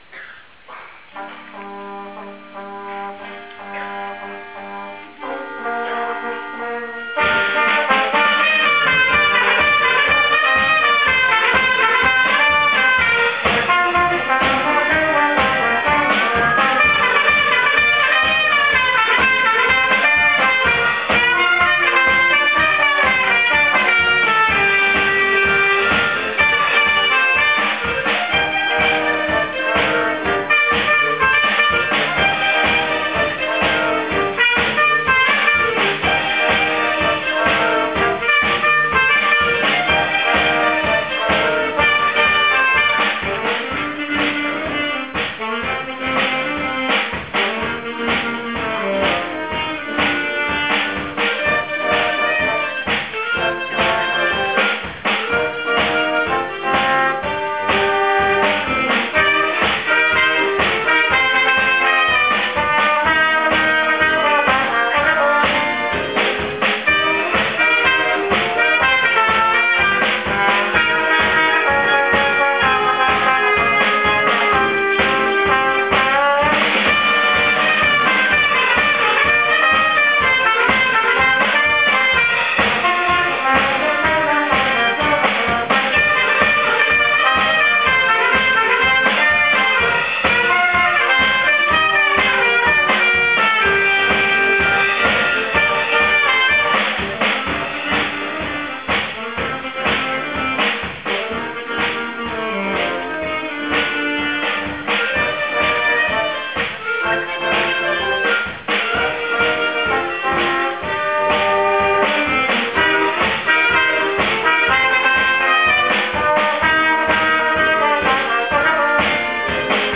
♪ 音 楽 会 ♪＜１１月２４日(日)＞
吹奏楽部が「音楽会」に出演しました。 １・２年生だけでの校外の初めての演奏でしたが、練習の成果を十分に発揮し少人数ながらも伸びやかな演奏を披露しました。